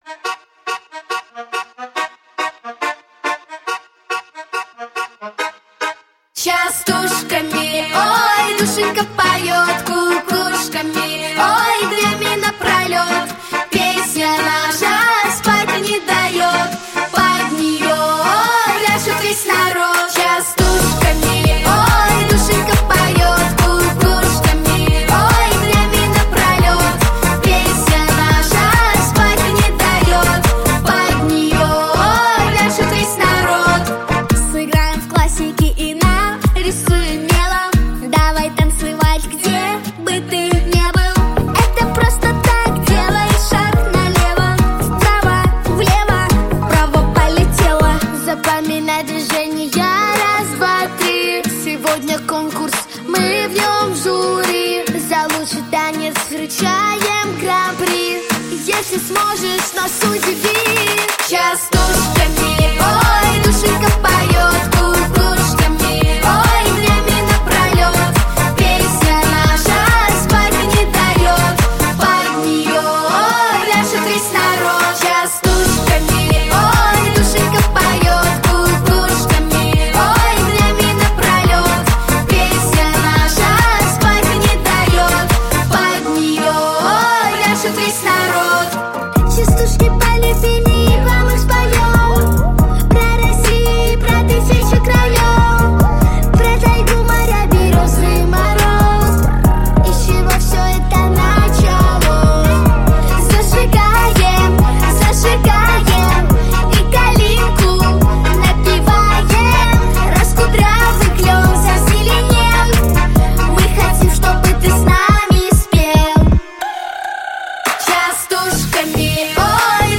• Жанр: Детские песни
народный мотив, детская дискотека
танцевальная